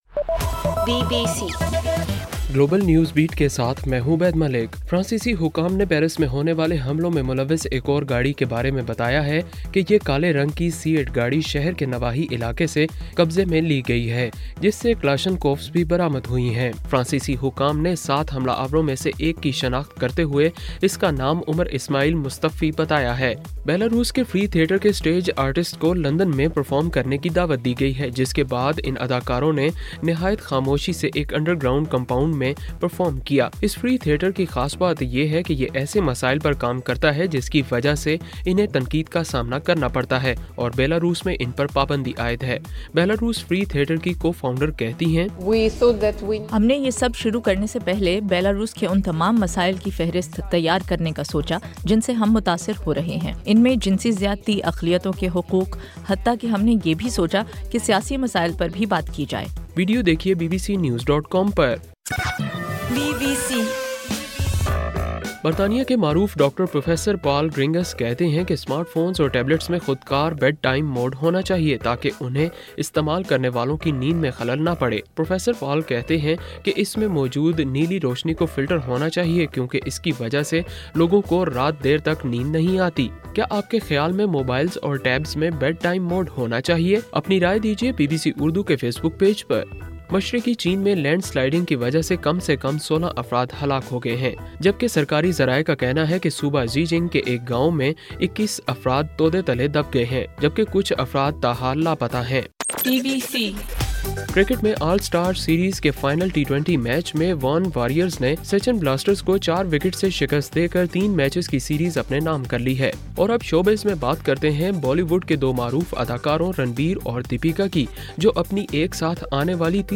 نومبر 15: رات 8 بجے کا گلوبل نیوز بیٹ بُلیٹن